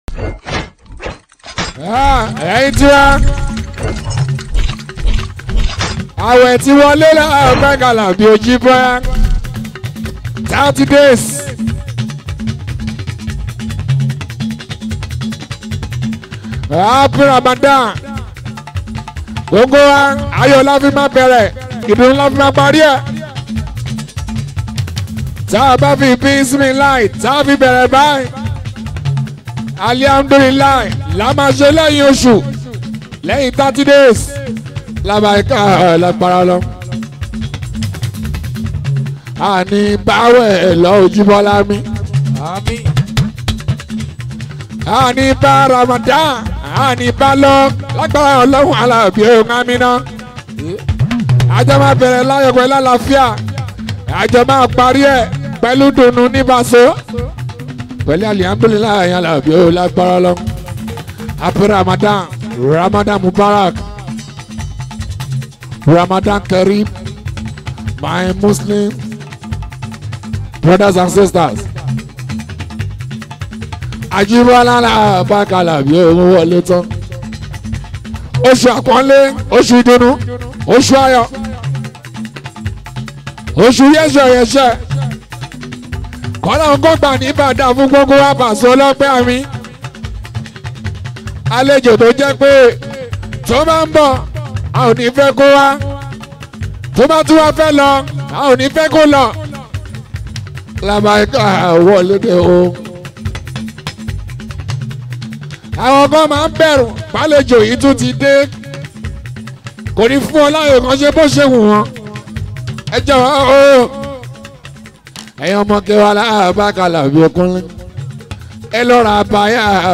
Fuji, Highlife, Yoruba Highlife, Yoruba Islamic Music